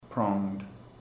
Προφορά
{prɔ:ŋd}